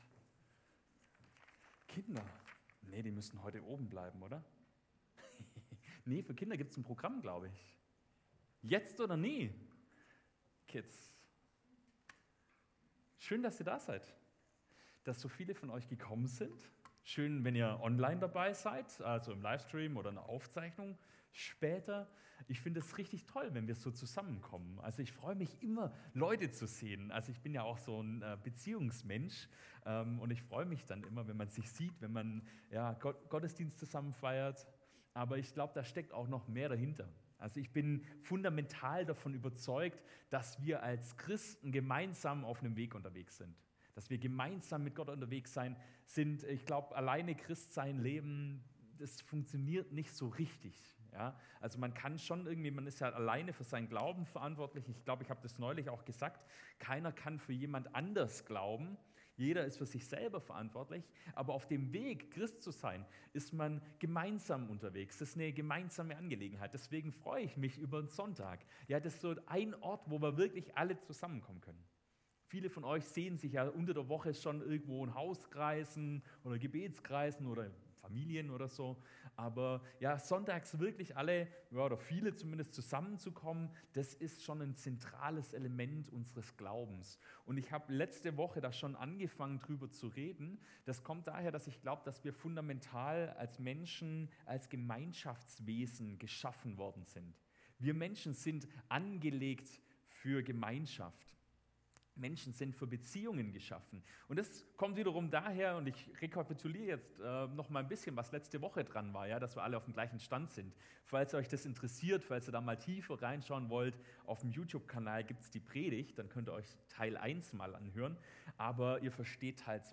Gottesdienst am 04.02.2024